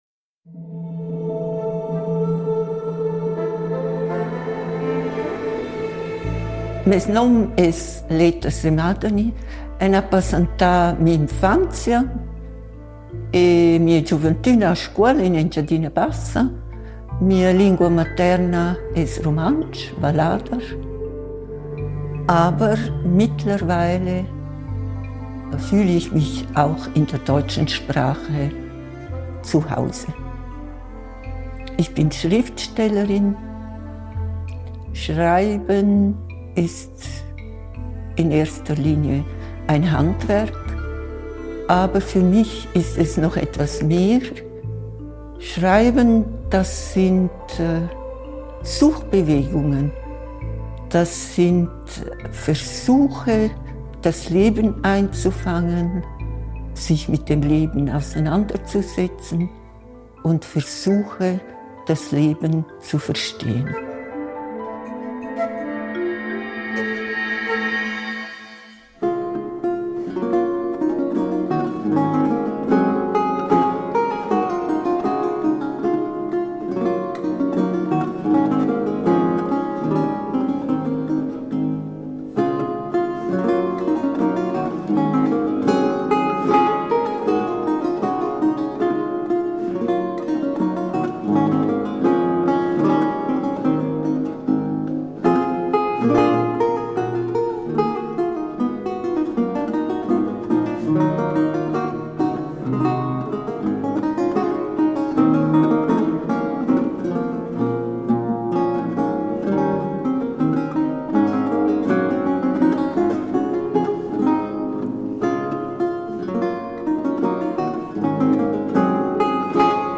Fra gli ospiti dell’edizione 2023 di Babel , il festival di letteratura e traduzione di Bellinzona, quest’anno dedicato al tema Isole, c’era la poetessa e narratrice engadinese Leta Semadeni , Gran Premio svizzero di letteratura, autrice di libri importanti come " In mia vita da vuolp/In meinem Leben als Fuchs " e " Tamangur " . Per l’occasione lo ha intervistata